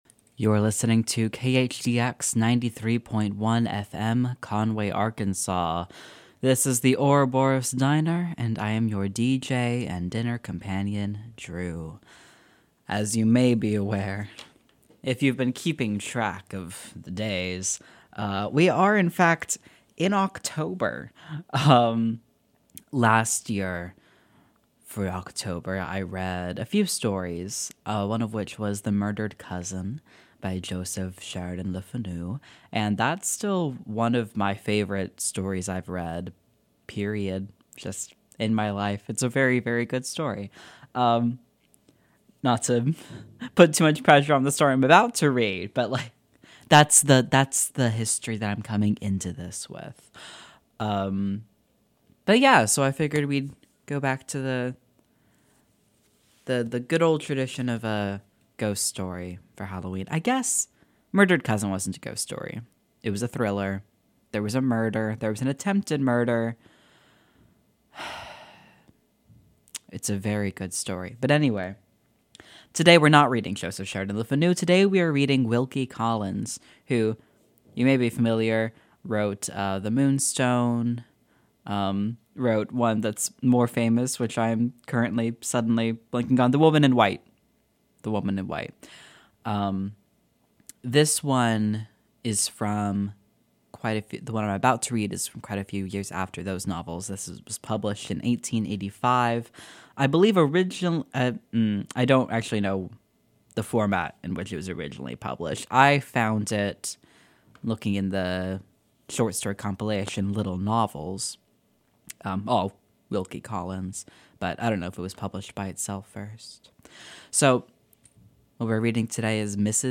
We’re in October, which means it’s time for me to read a little ghost story! This week I started on “Mrs. Zant and the Ghost” by Wilkie Collins, who’s best known for The Woman in White and The Moonstone.